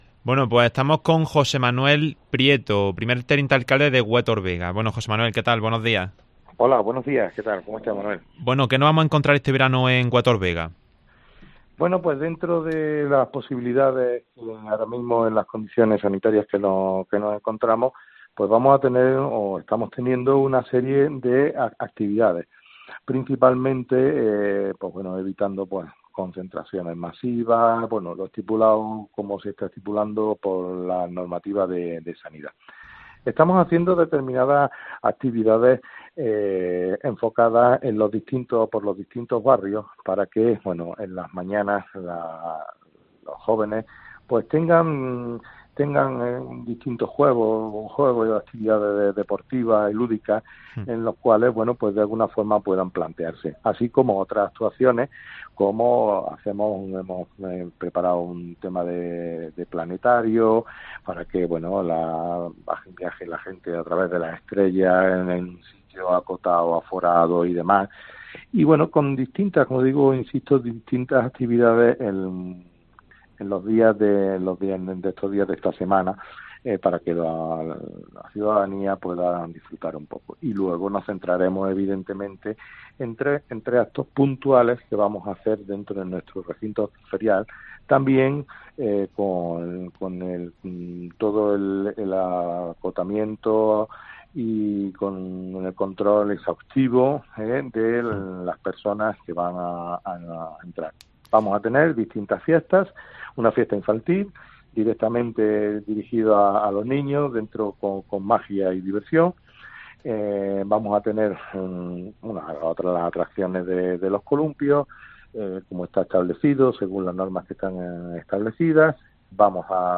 En COPE, hemos hablado con el primer teniente alcalde de Huétor Vega, José Manuel Prieto.